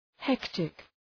Προφορά
{‘hektık}